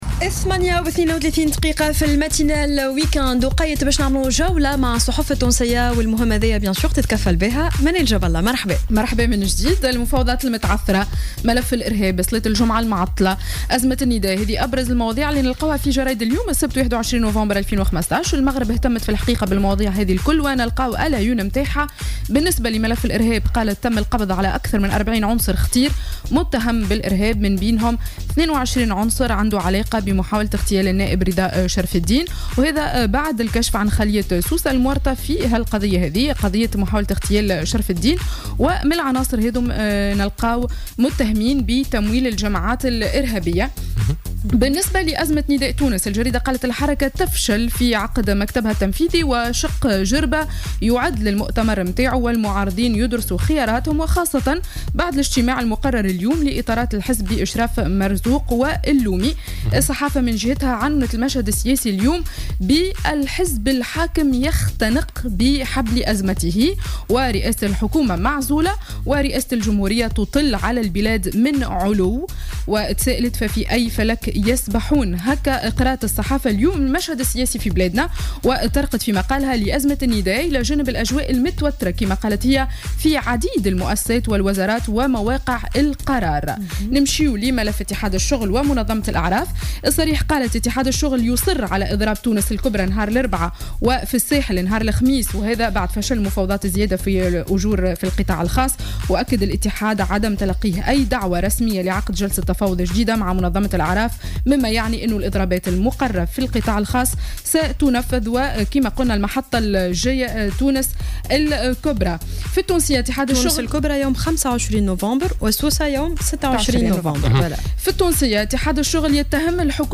معرض الصحافة ليوم السبت 21 نوفمبر 2015